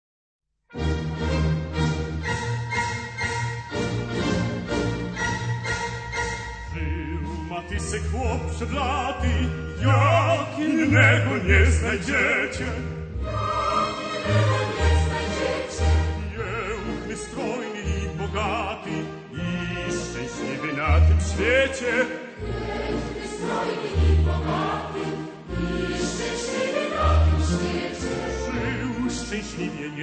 Archival recordings from years 1961-1970